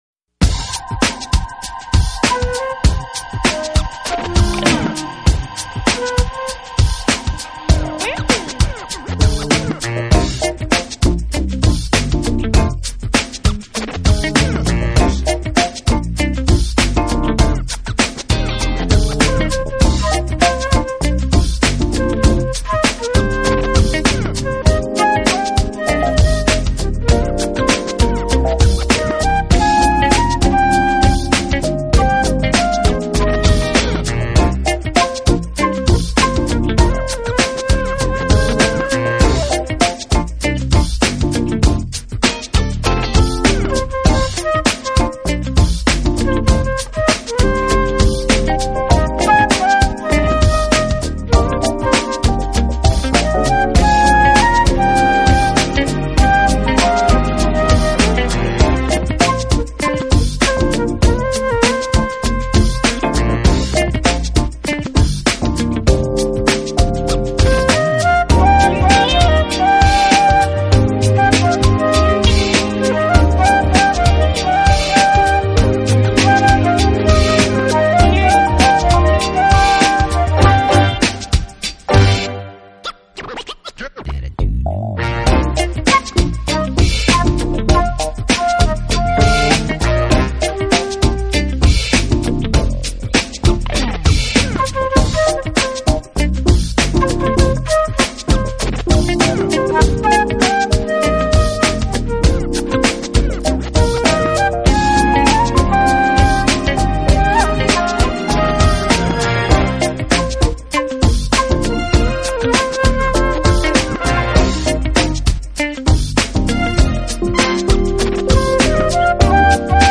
音乐风格：Jazz